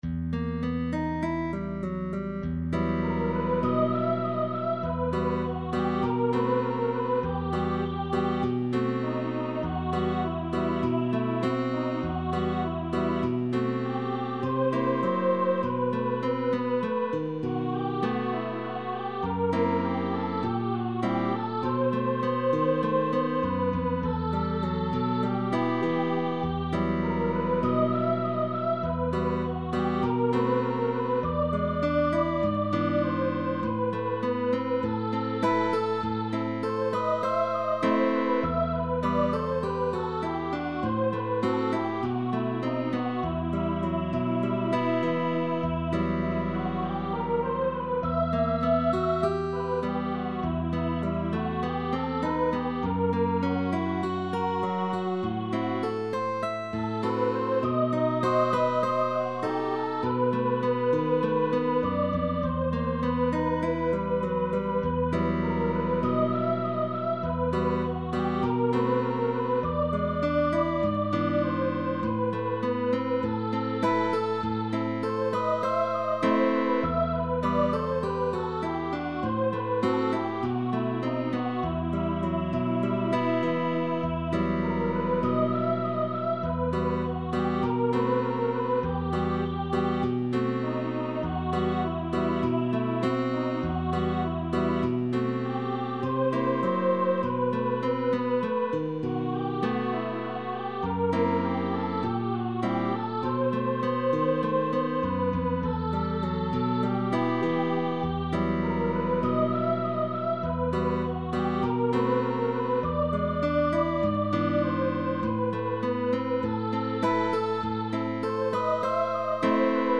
SOPRANO & GUITAR Bolero (Mexican melodic song).